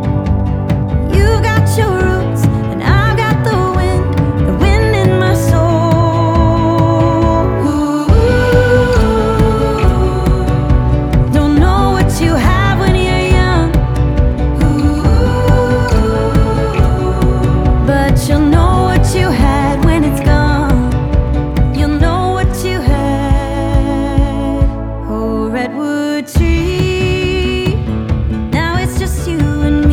Genre: Country